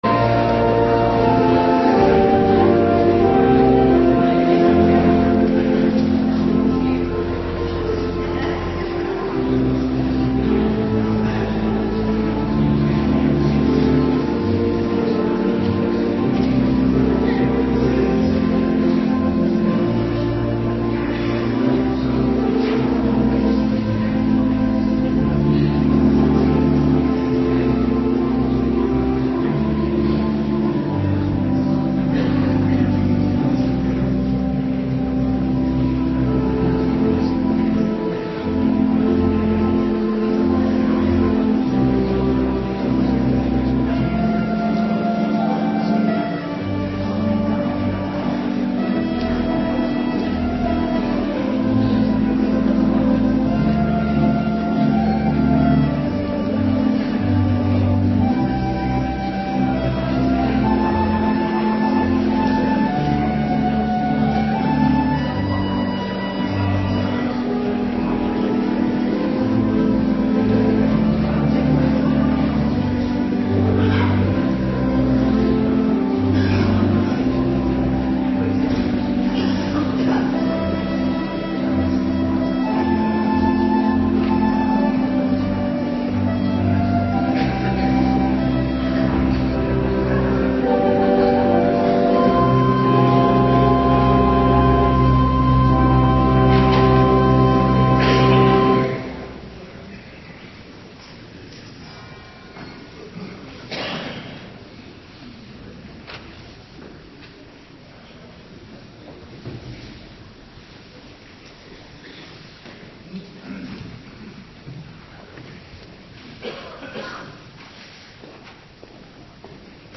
Morgendienst 15 maart 2026